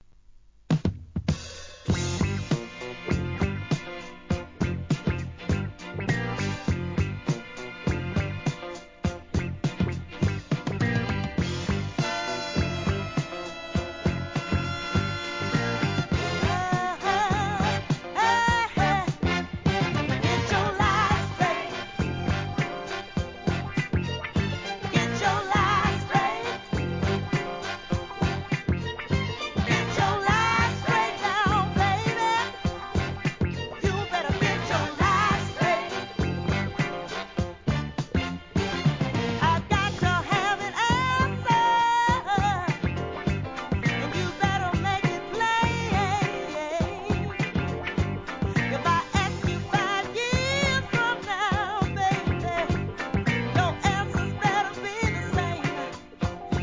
¥ 1,100 税込 関連カテゴリ SOUL/FUNK/etc...
力強いヴォーカルで歌い上げる好FUNKナンバー!!